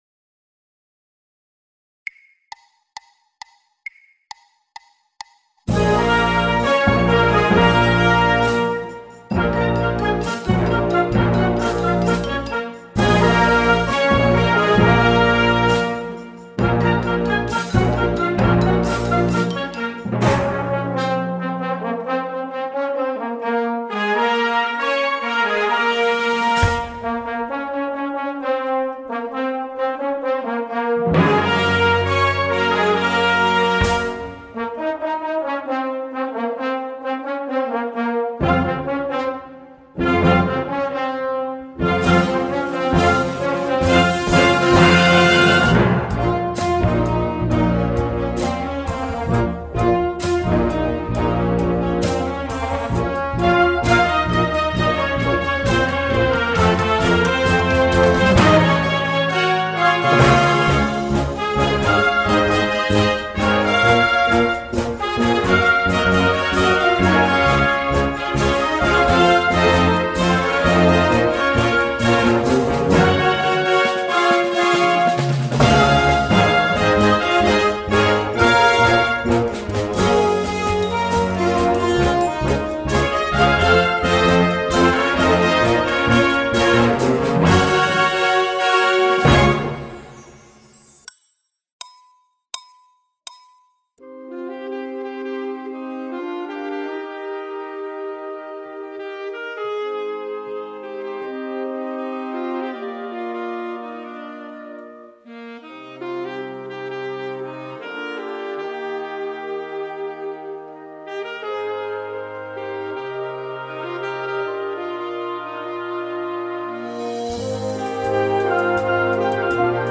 Gesamtaufnahme mit Anfangstempo 132 bpm
The Greatest Showman_Hannover-Version_132bpm.mp3